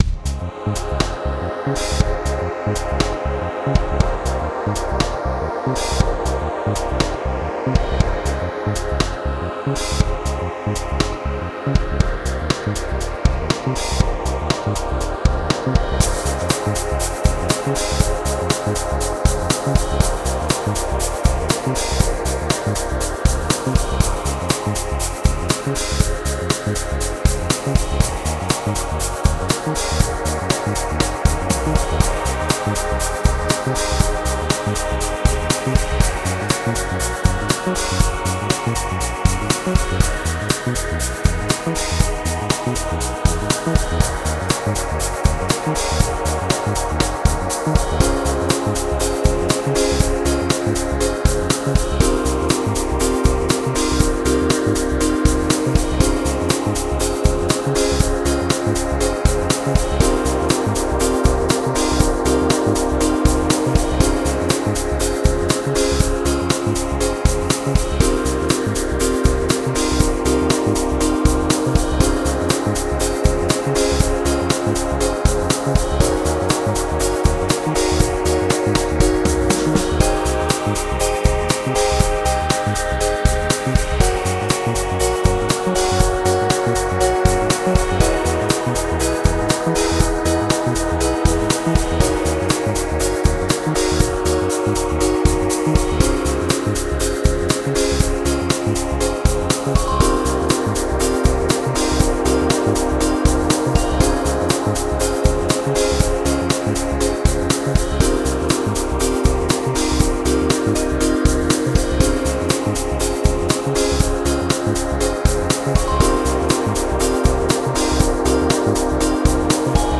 4 minute, loopable, N64 Mario inspired ocean/beach VGM..